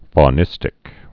(fô-nĭstĭk)